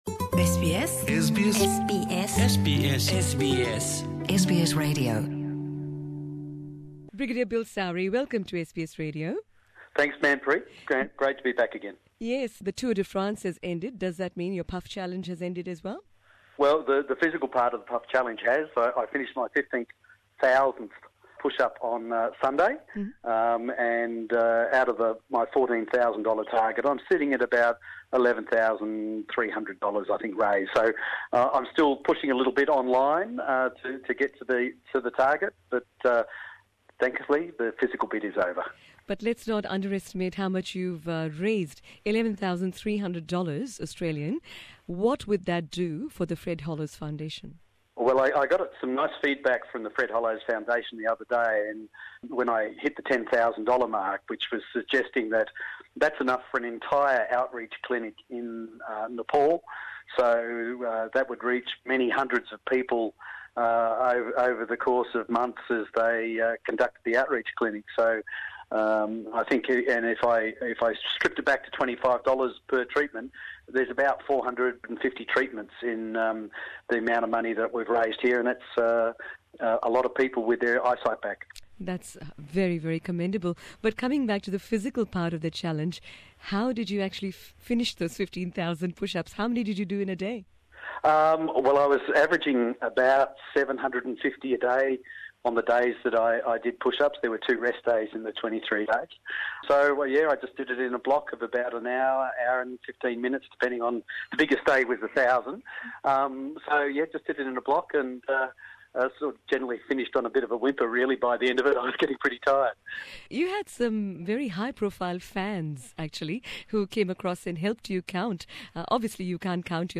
Here is a special interview